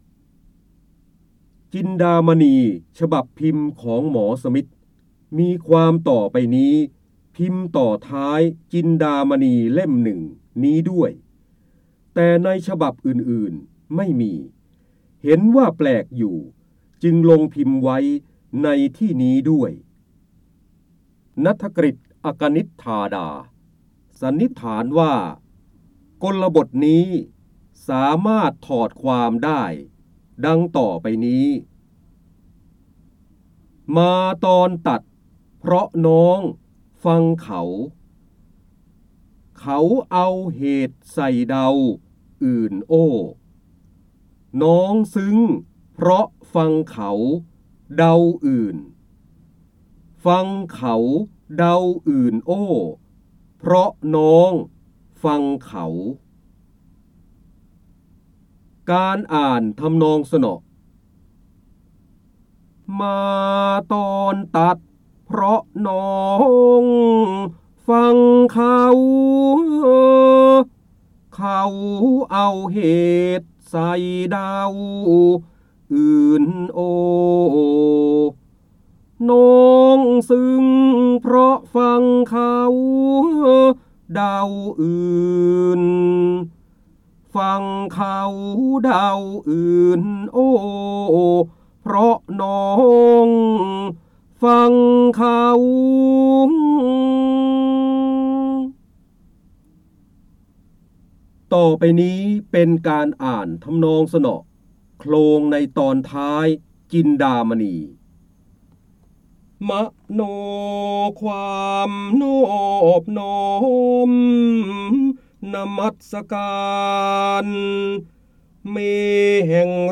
เสียงบรรยายจากหนังสือ จินดามณี (พระโหราธิบดี) จินดามณีฉบับพิมพ์ของหมอสมิธมีความต่อไปนี้
คำสำคัญ : การอ่านออกเสียง, พระเจ้าบรมโกศ, ร้อยกรอง, จินดามณี, พระโหราธิบดี, ร้อยแก้ว